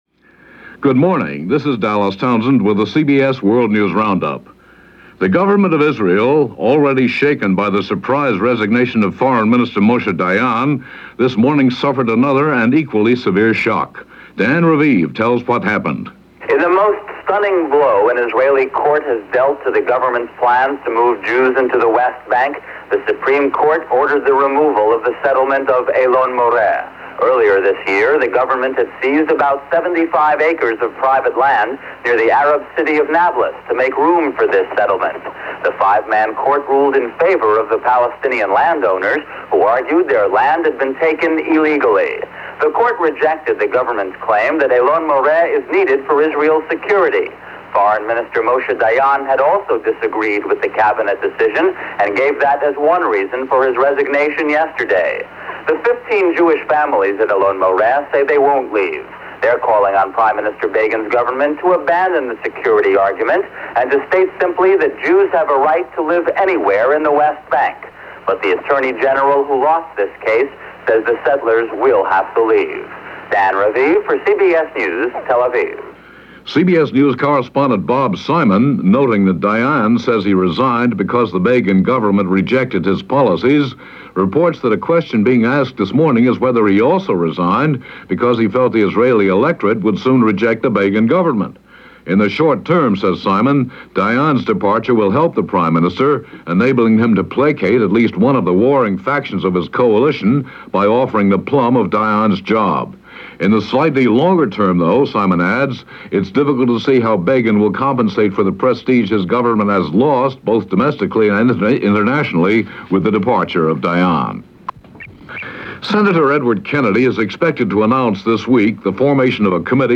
And that’s just a sample of what went on during this October 22nd in 1979, as reported by the CBS World News Roundup.